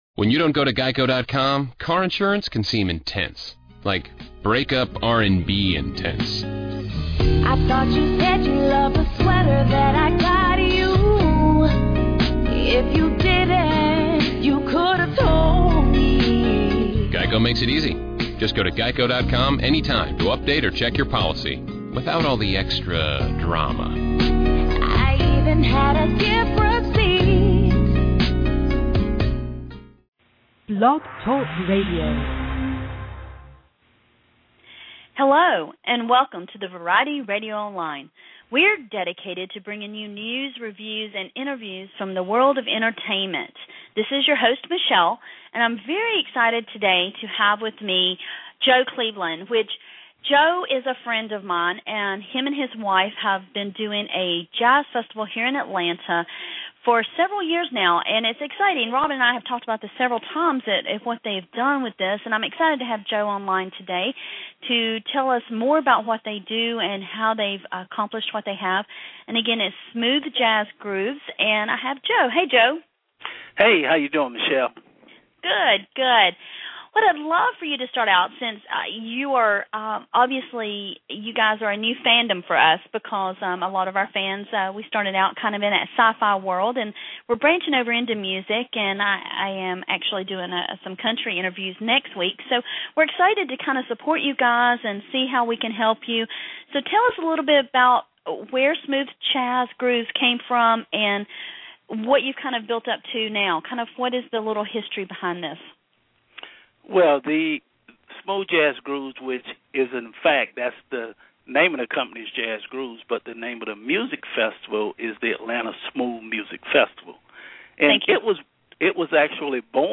Interviews / Atlanta Smooth Jazz Festival